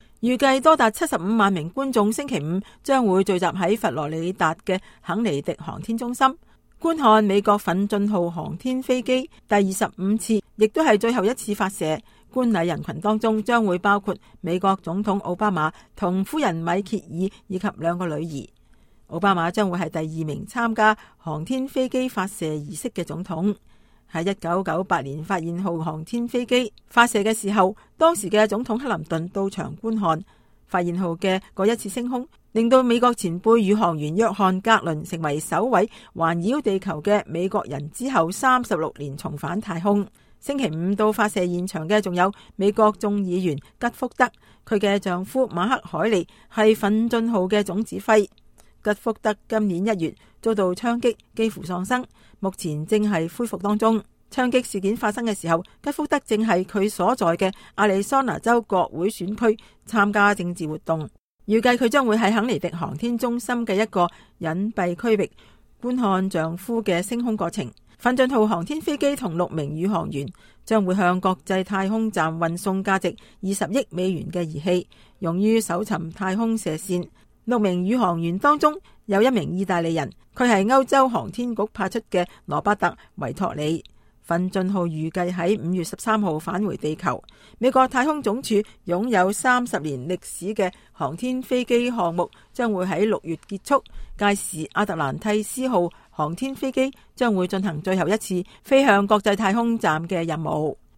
CAN-News_US_Space.Mp3